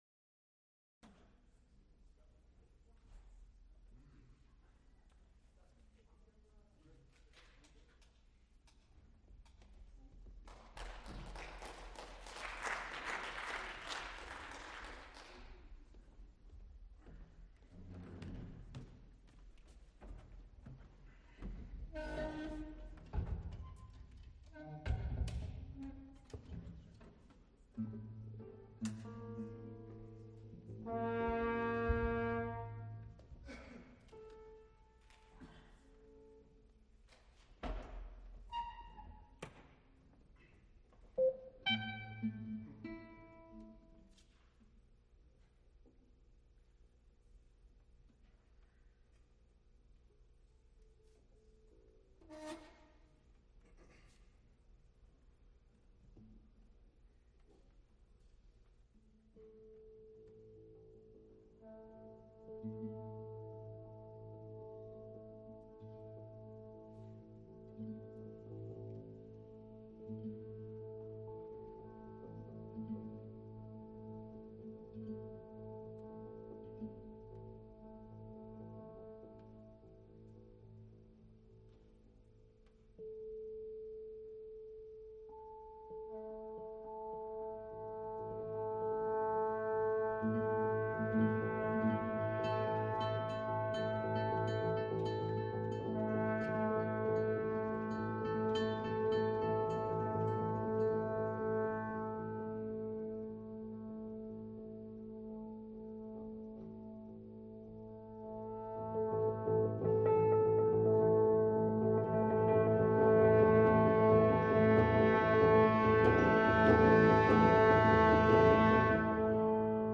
Recorded live December 11, 1979, Frick Fine Arts Auditorium, University of Pittsburgh.
Extent 2 audiotape reels : analog, quarter track, 7 1/2 ips ; 12 in.
musical performances
Instrumental ensembles Trombone music (Trombones (2)) Improvisation (Music)